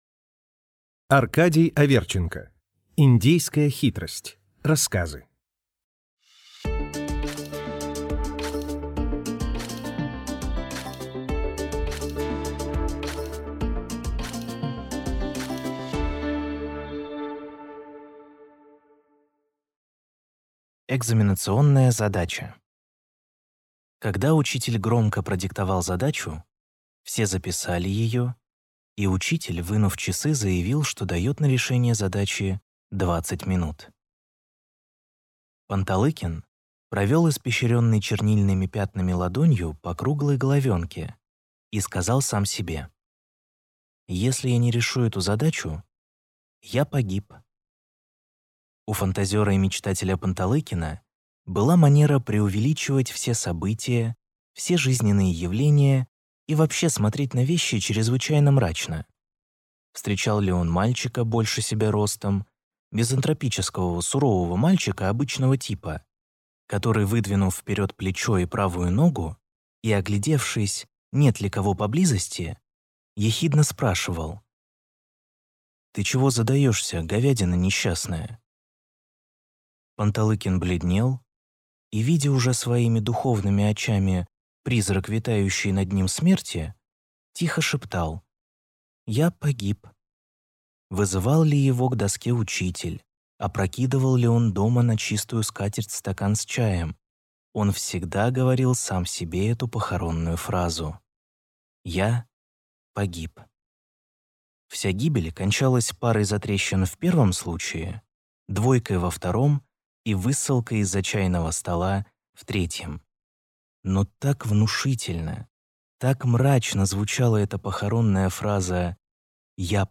Аудиокнига Индейская хитрость | Библиотека аудиокниг